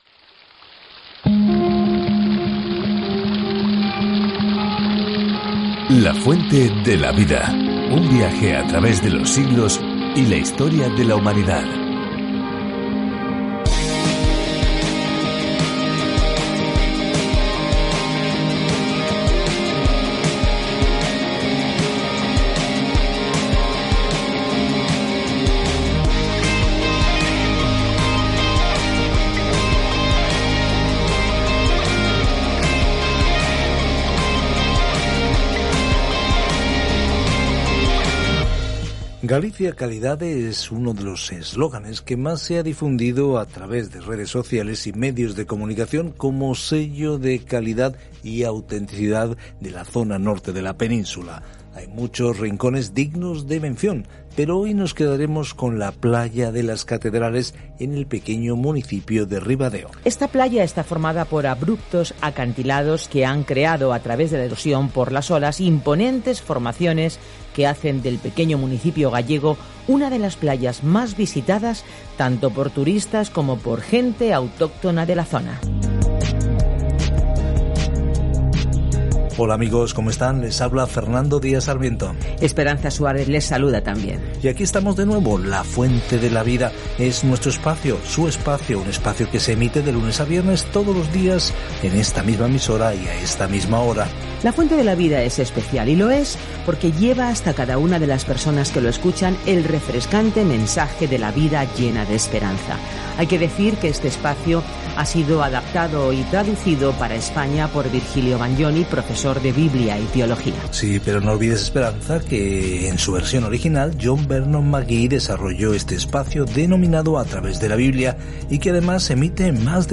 Viaja diariamente a través de 2 Corintios mientras escuchas el estudio en audio y lees versículos seleccionados de la palabra de Dios.